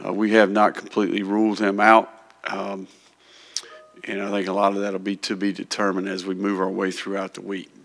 Lagway’s hamstring injury suffered in the second quarter of Saturday’s 34-20 loss to No. 2 Georgia was “less significant than initially feared” and he’s not ruled out for Florida’s game Saturday in Austin, Florida coach Billy Napier said in his Monday news conference.
Napier said Lagway is not ruled out for this week: